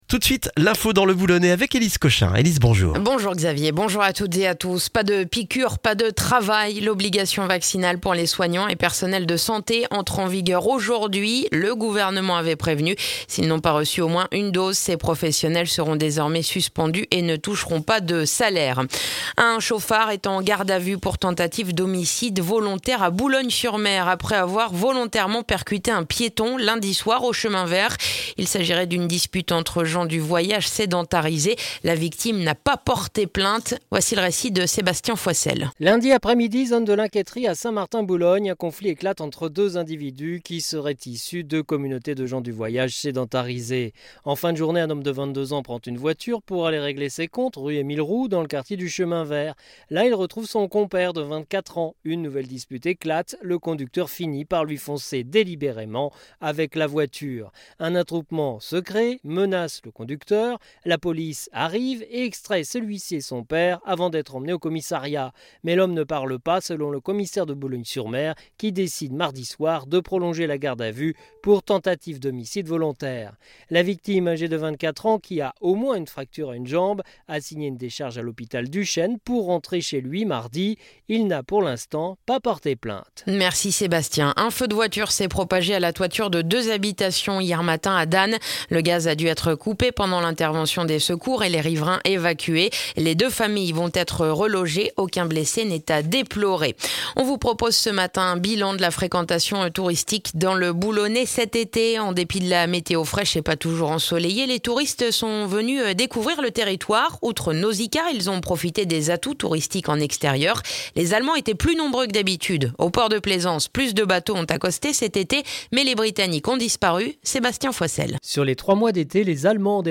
Le journal du mercredi 15 septembre dans le boulonnais